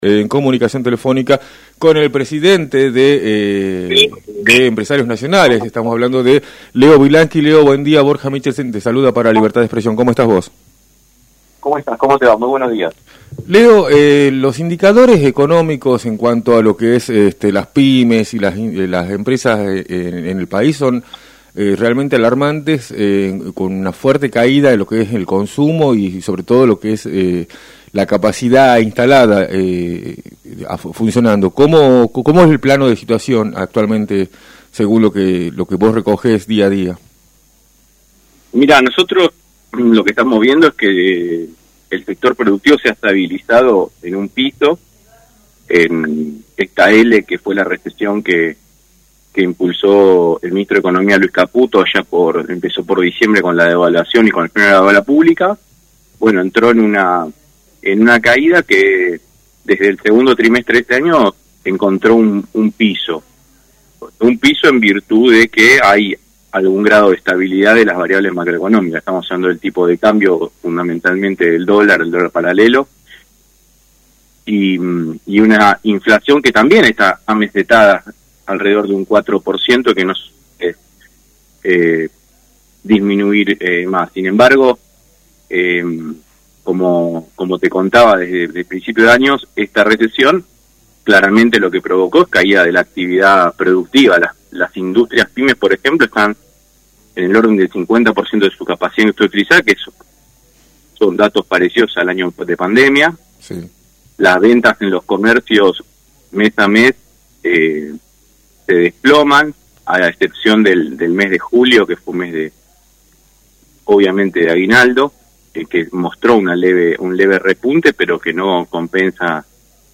En entrevista Libertad de Expresión, por Rock & Pop